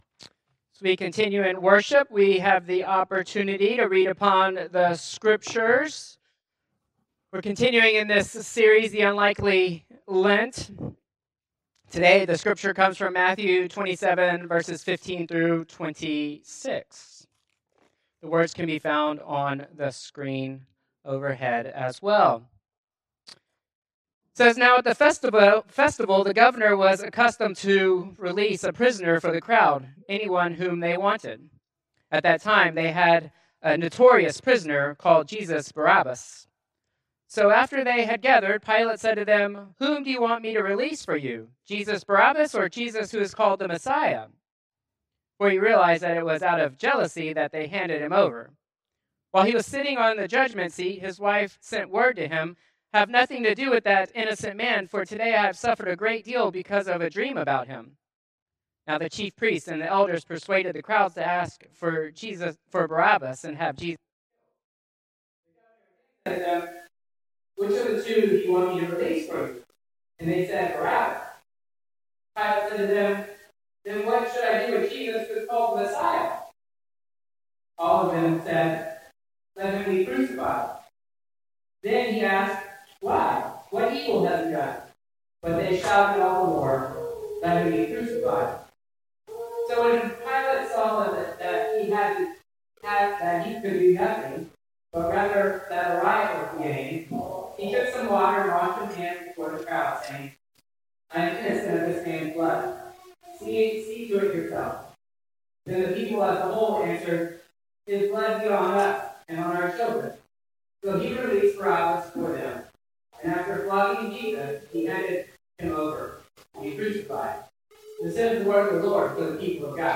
Traditional Worship 3-8-2026